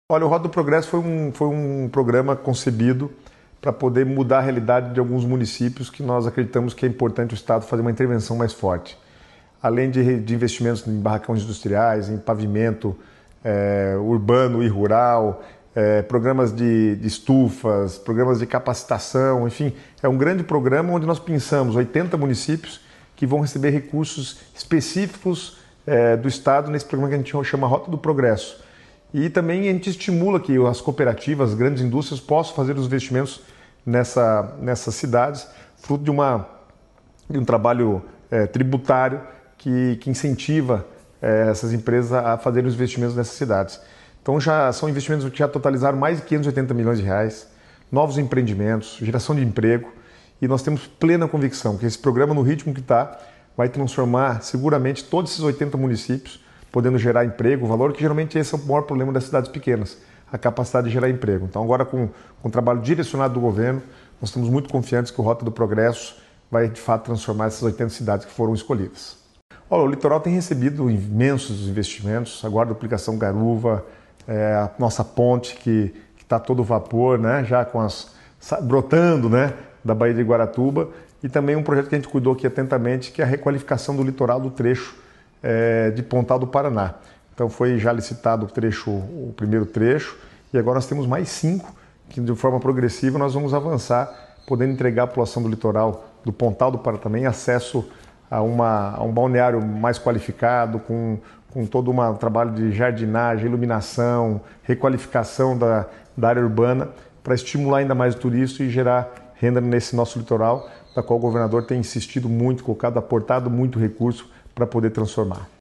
Sonora do secretário do Planejamento, Guto Silva, sobre os programas desenvolvidos pela pasta em 2024 | Governo do Estado do Paraná